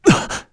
Esker-Vox_Damage_kr_01.wav